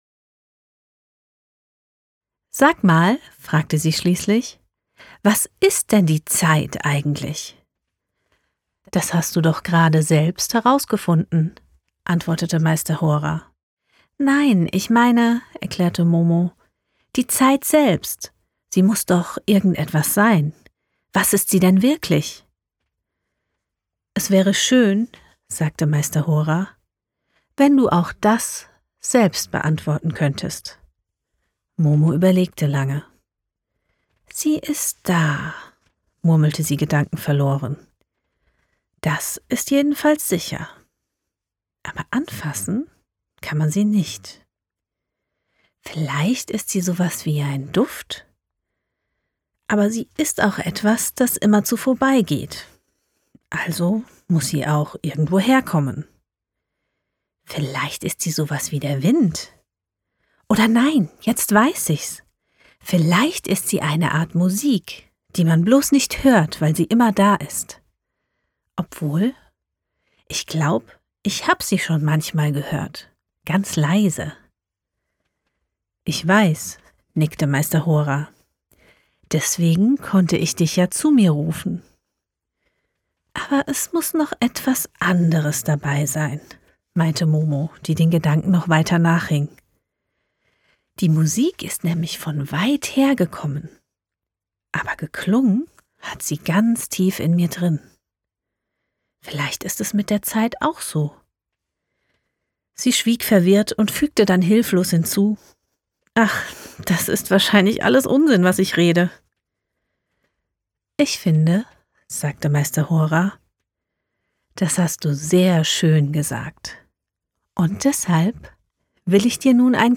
Professionelle Studiosprecherin.
Dialog